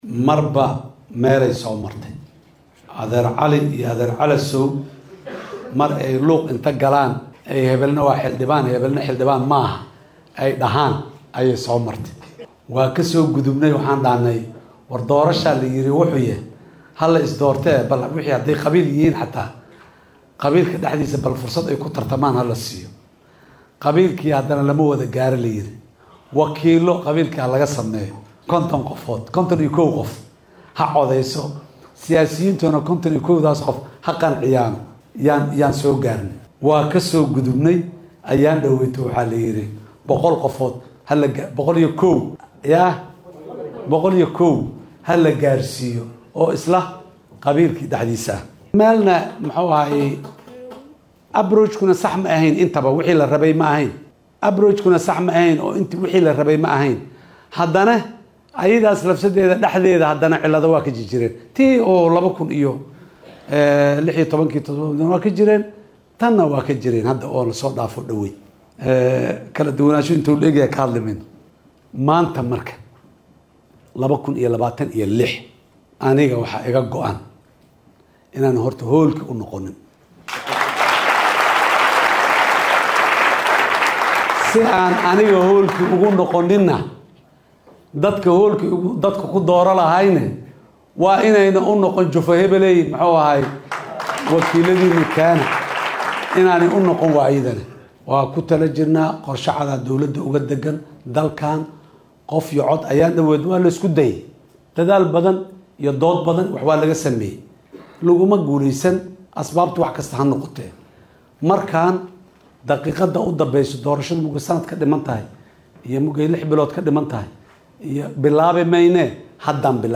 Madaxweynaha Jamhuuriyadda Federaalka Soomaaliya Xasan Sheekh Maxamuud oo ka qeyb-galay Dood-furan oo si toos ah ay shacabka Soomaaliyeed ugu soo weydiinayeen